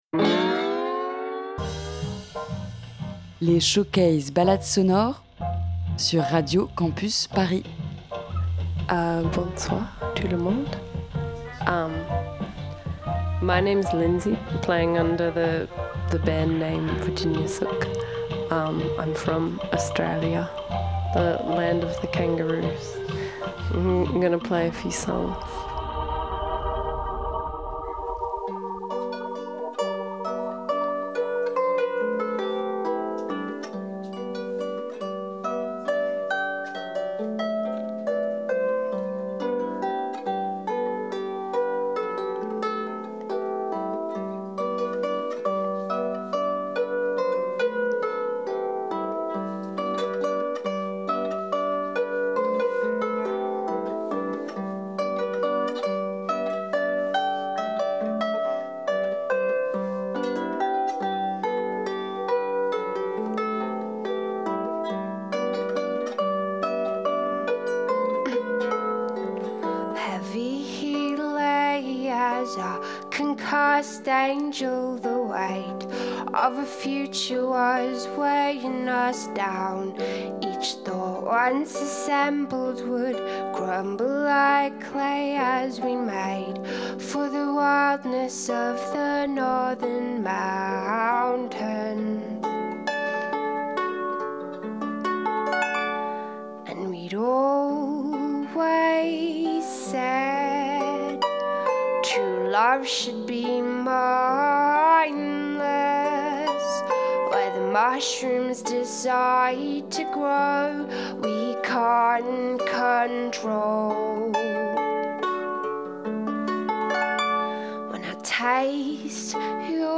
un groupe australien
chanteuse/guitariste/harpiste